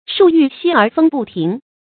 树欲息而风不停 shù yù xī ér fēng bù tíng
树欲息而风不停发音